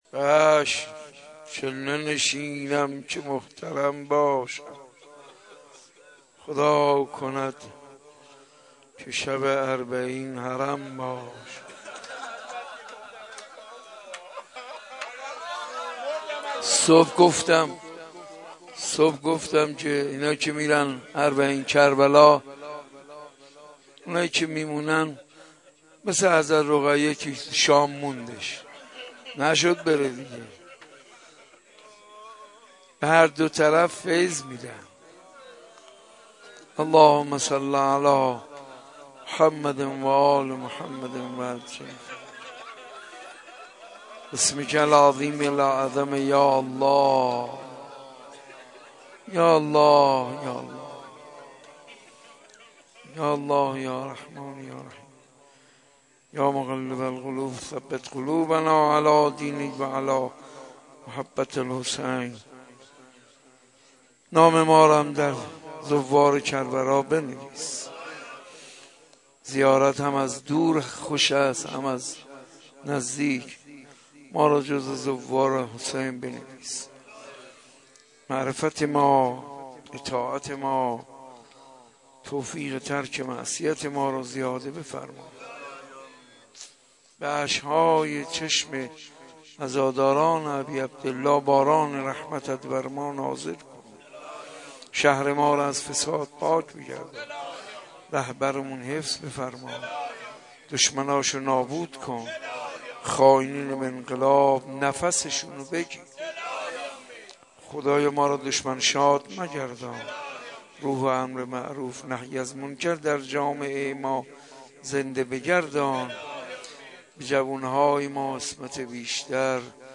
نوحه
نوحه مداحی